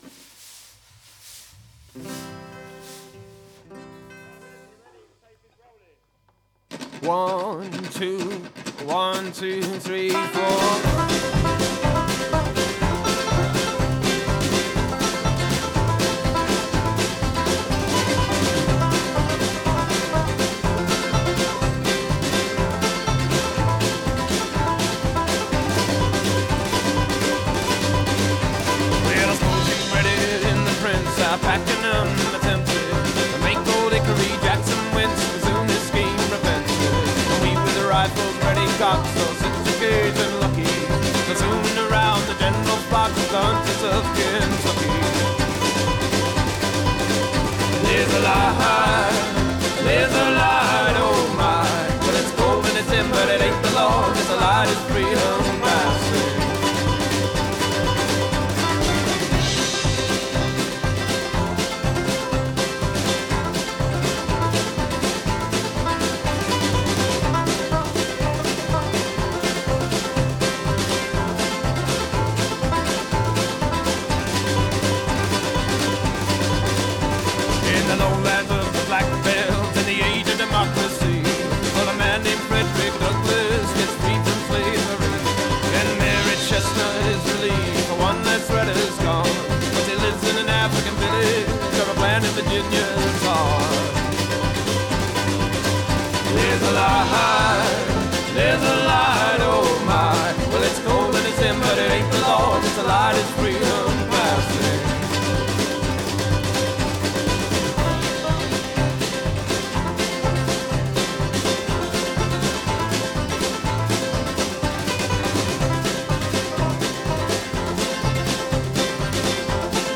Great Americana for your listening pleasure.